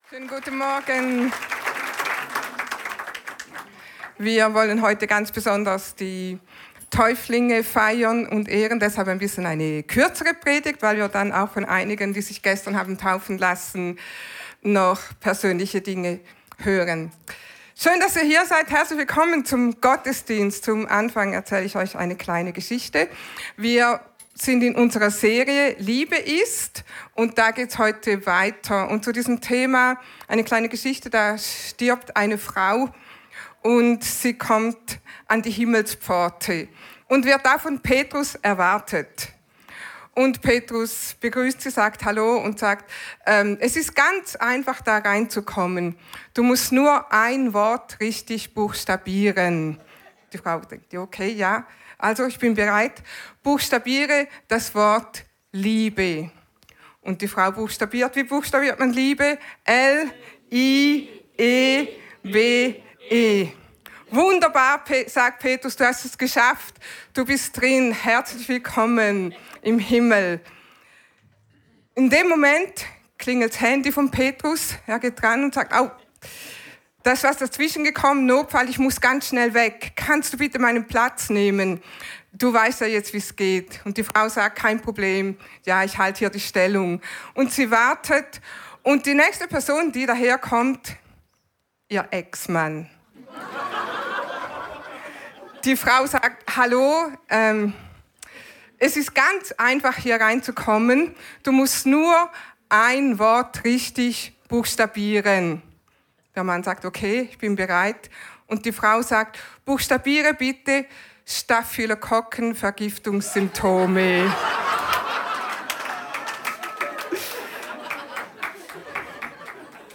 Alle Predigten aus den Sonntagsgottesdiensten